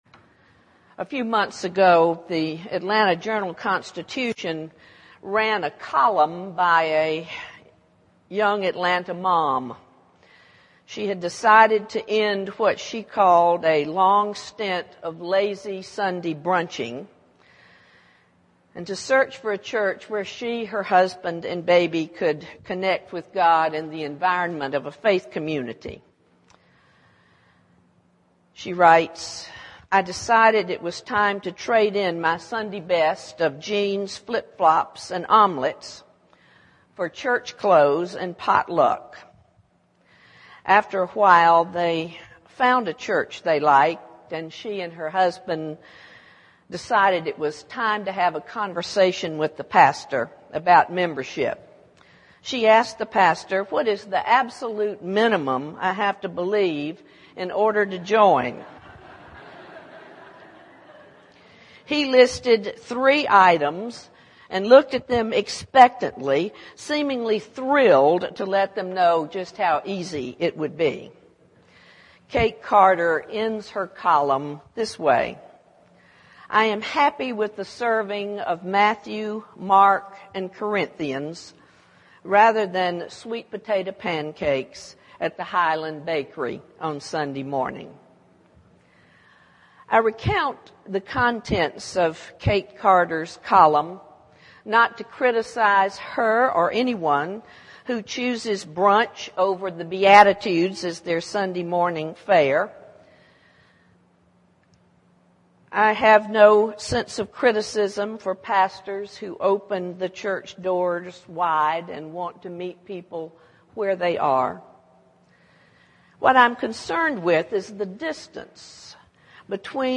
THE SERMON "Sunday Brunch"
sermonAB_jun01.mp3